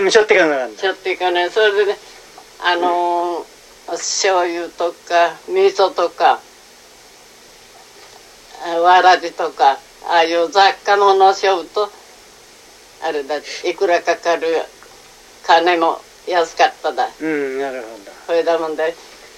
「みそ（味噌）」のアクセントに注目して聞いてみましょう。
最初の「みそ（とか）」のアクセントは「高低」、次の「みそ（は）」のアクセントは「低高」になっています。
このように、同一の話し手であっても、アクセントが安定しないのが、井川方言の特徴です。